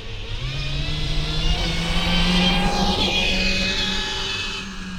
Subjective Noise Event Audio File (WAV)